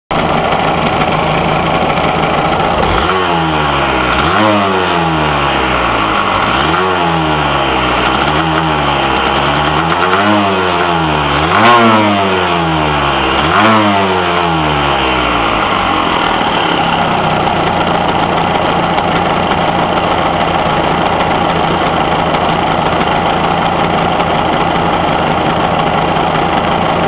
Et ici encore 25 secondes mais en mettant en plus quelques coups de gaz:
Je sais pas vous mais moi je le sens bizarre ce ralenti.